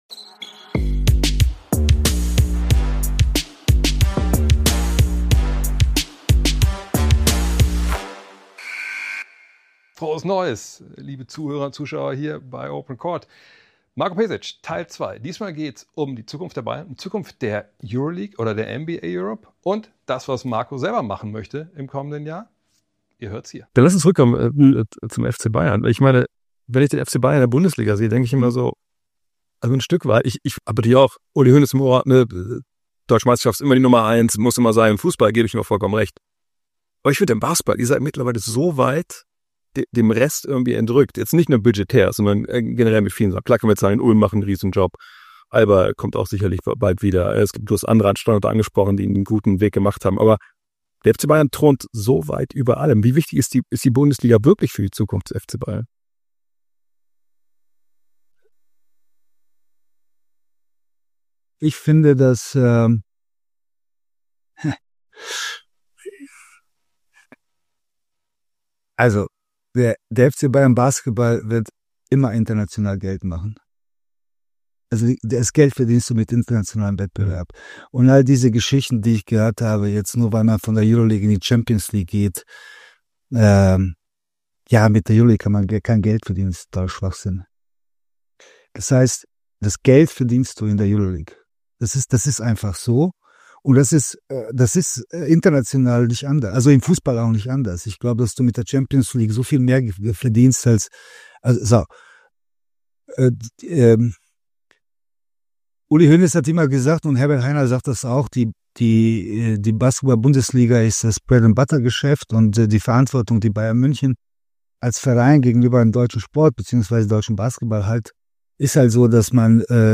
Nur der Zutritt zur FCBB-Kabine ist weder dem Traumschiffkapitän Florian Silbereisen, DJ Ötzi und nicht mal Roland Kaiser gestattet – also ist Marko Pesic hier eingetroffen zur allerletzten Amtshandlung als Geschäftsführer der Bayern, die er zum 1. Januar nun tatsächlich verlässt nach knapp 15 Jahren, zumindest in verantwortlicher Position: für ein ausführliches Gespräch bei OPEN COURT, dem Podcast seines Vereins, dessen Baumeister, Fieberthermometer, Impulsgeber, Halsschlagader, Stratege, Antreiber, Sprachrohr und Stratege er so lange war.